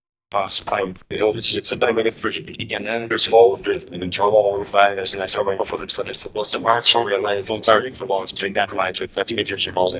An a cappella of a beautiful female voice with jazz style singing from the 50s, 60s,70s, 80s,90s, in English with an American accent 0:47 Created Apr 13, 2025 4:28 PM som de programa de documentario que passava na tv nos anos 50, programa de misterio 0:10 Created Jun 2, 2025 2:42 PM
som-de-programa-de-docume-lvvqzrvs.wav